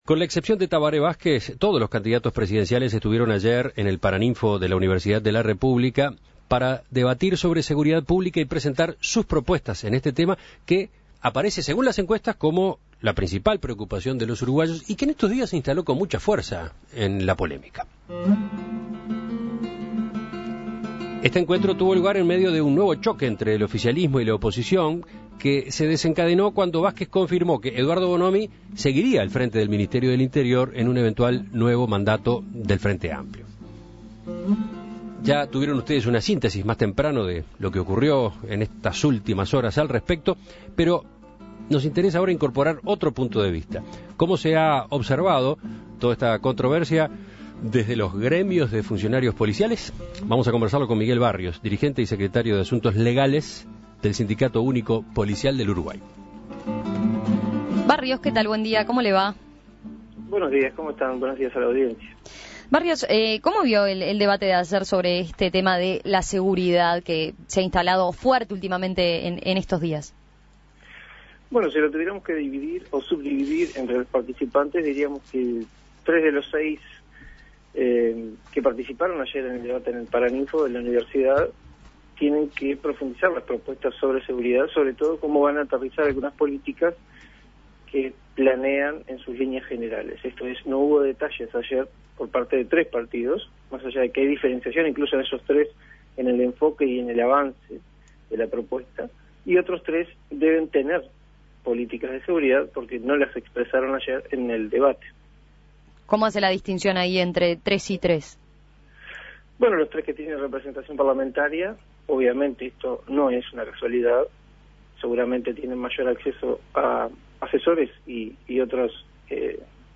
En diálogo con En Perspectiva